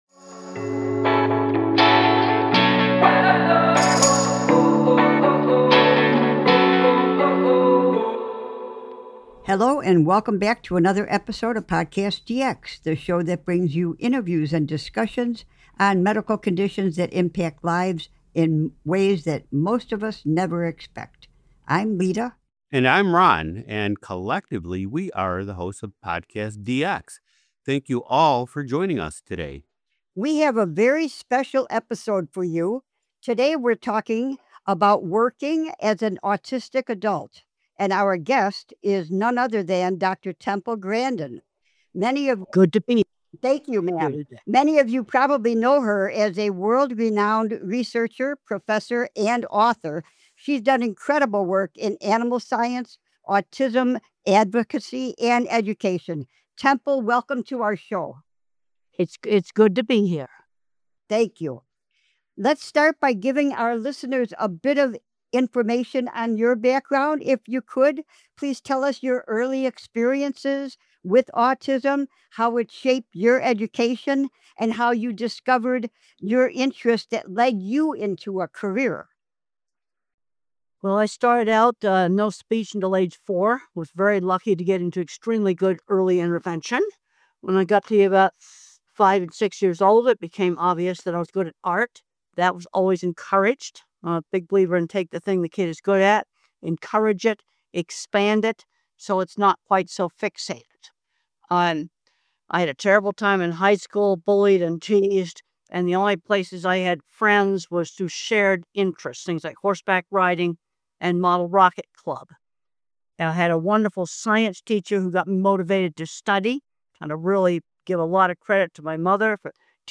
Temple Grandin PhD is our distinguished guest this week.